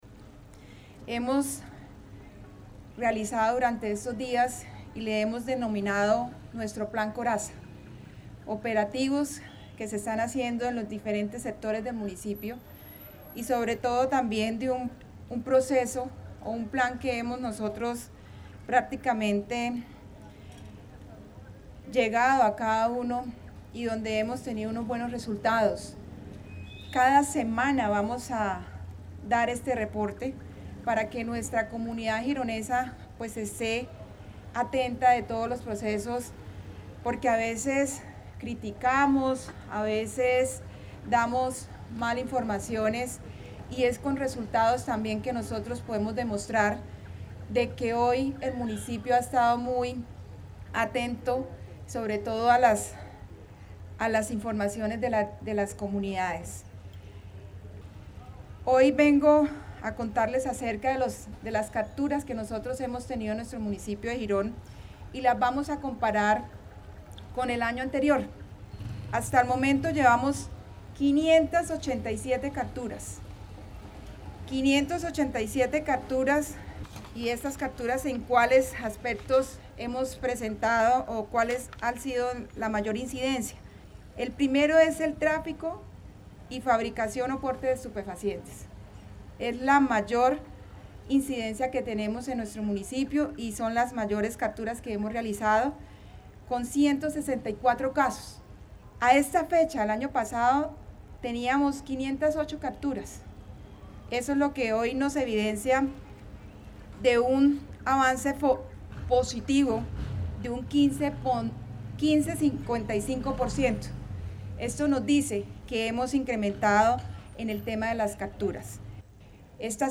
ALCALDESA YULIA RODRÍGUEZ.mp3